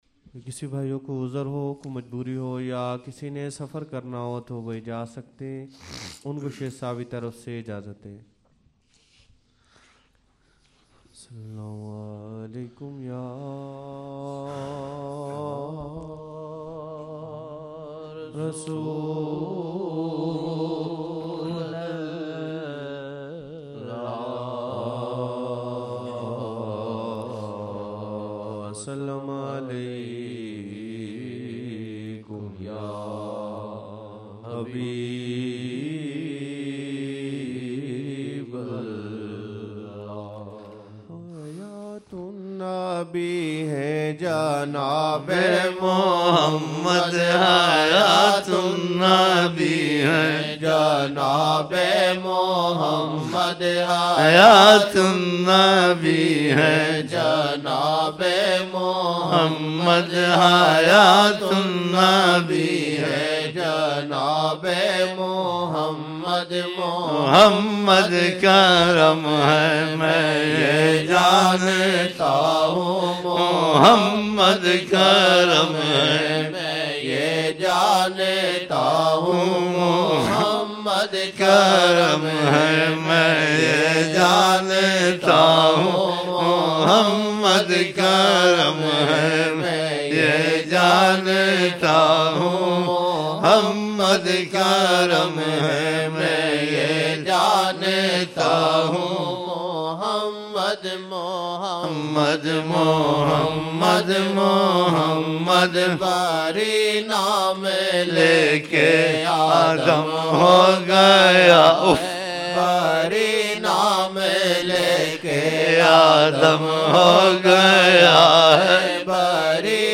فجر محفل